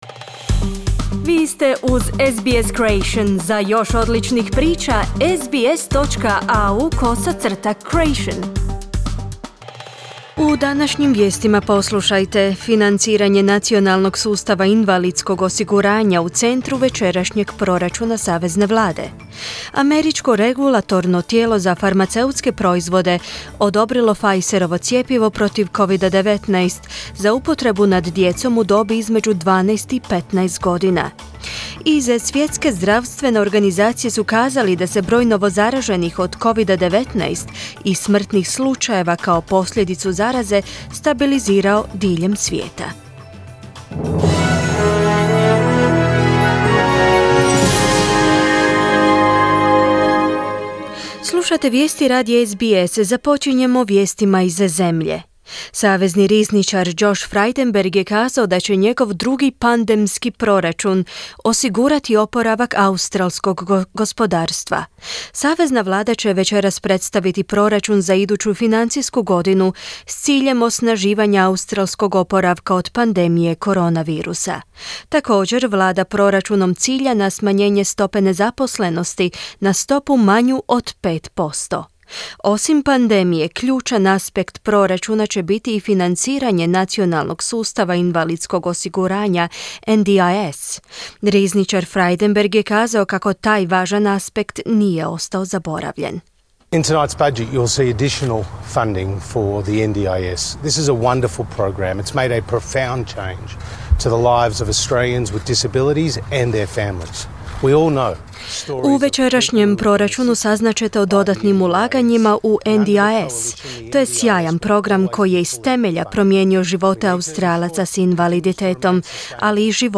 Vijesti, 11.5.2021.
Vijesti radija SBS na hrvatskom jeziku.